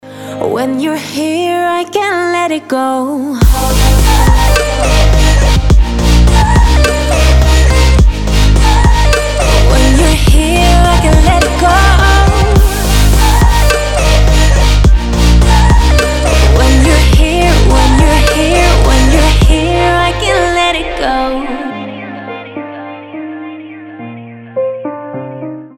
• Качество: 320, Stereo
Electronic
EDM
красивый женский голос
future bass